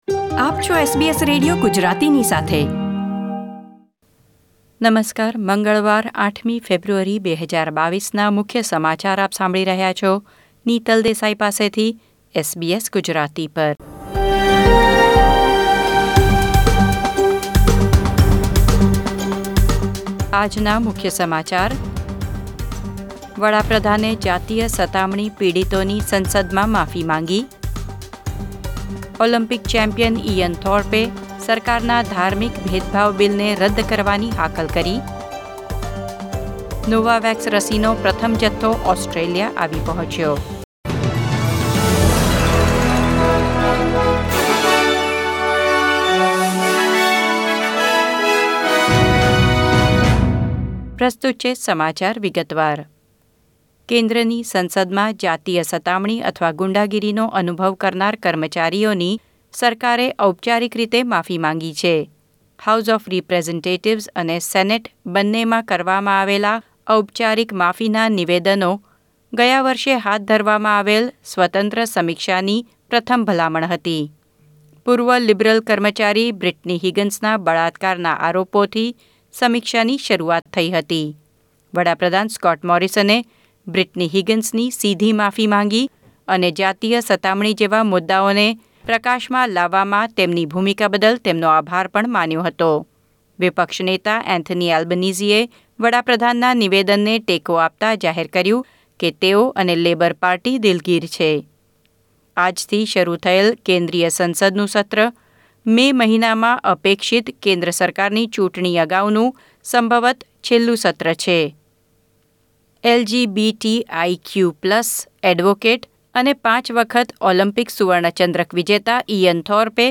SBS Gujarati News Bulletin 8 February 2022